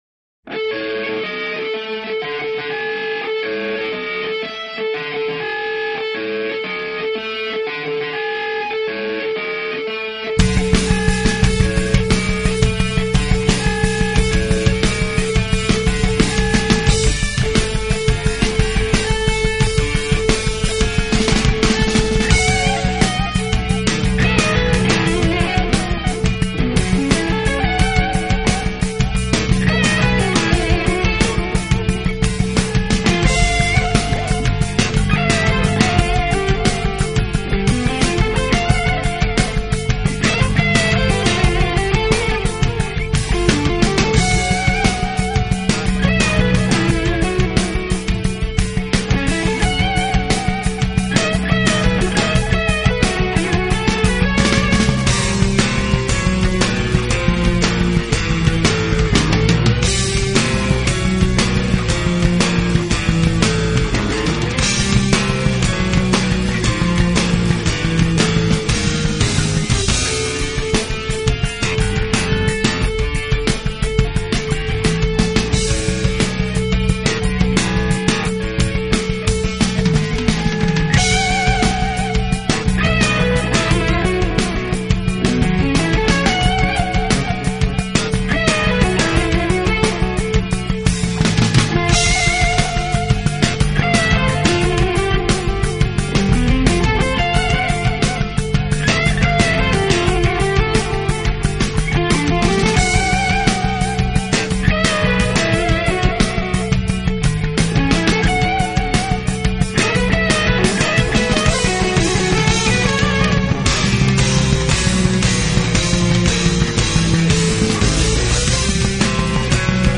【Fusion爵士】Fusion三人组